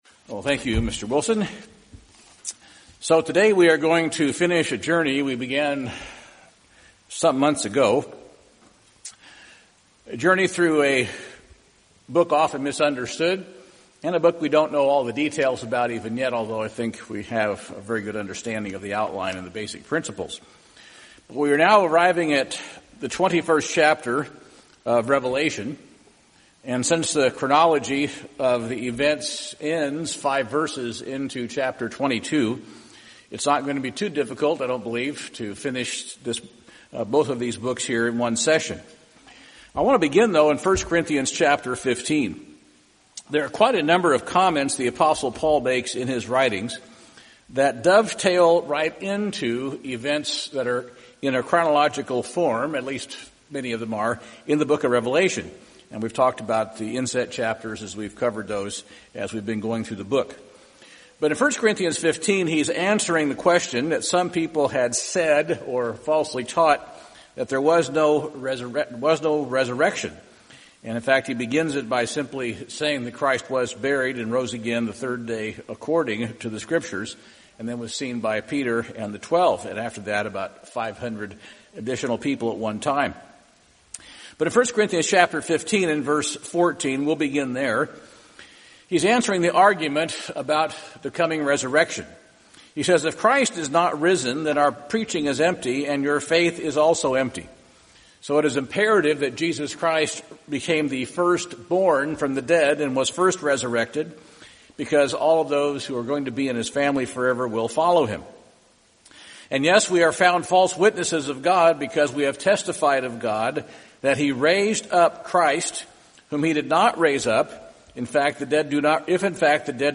Given in Portland, OR